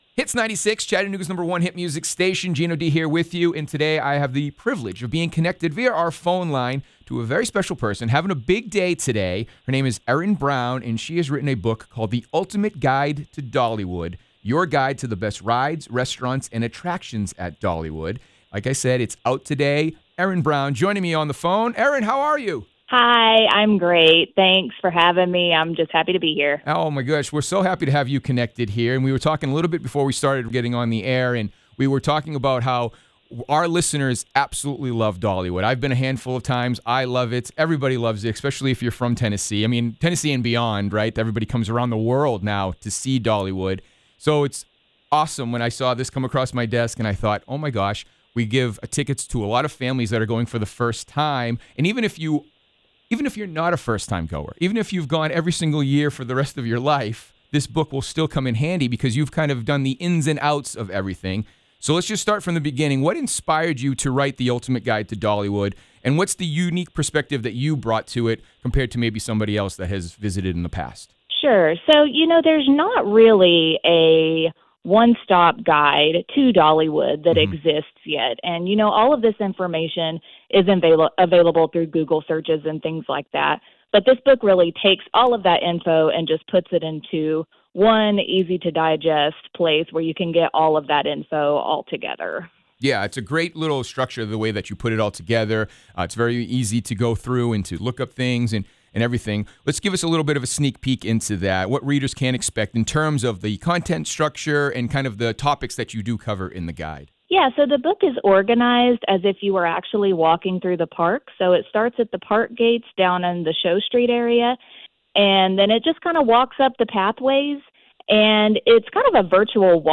Dollywood-Book-Full-Interview.wav